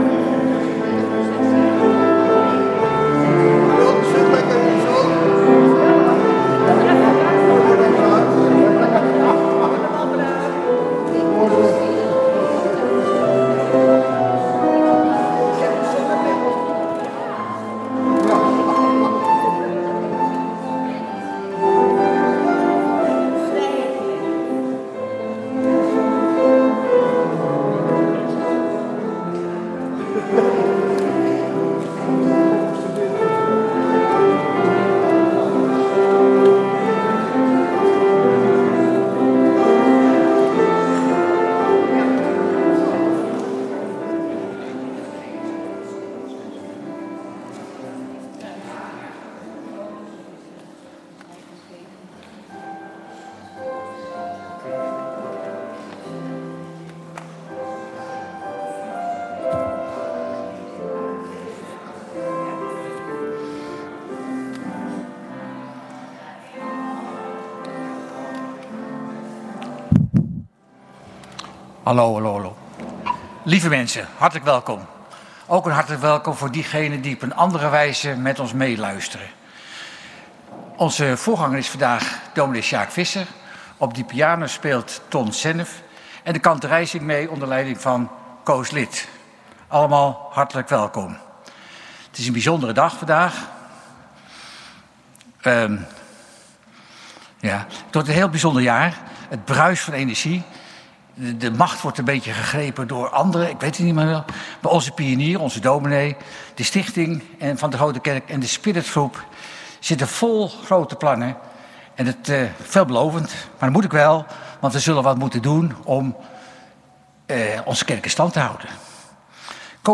Kerkdienst geluidsopname